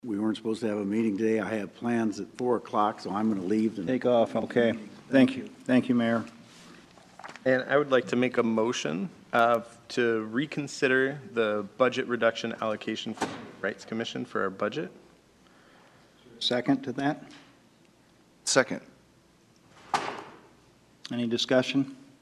AFTER THE VOTE ON THE TWO CITY BOAT MARINA ISSUES TOOK PLACE AT MONDAY’S SPECIAL CITY COUNCIL MEETING,
O’KANE MADE A MOTION AS MAYOR BOB SCOTT LEFT THE ROOM TO RECONSIDER THE BUDGET CUT TO THE CITY’S HUMAN RIGHTS COMMISSION: